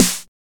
Prominent Acoustic Snare F# Key 31.wav
Royality free steel snare drum tuned to the F# note. Loudest frequency: 4263Hz
prominent-acoustic-snare-f-sharp-key-31-9wP.ogg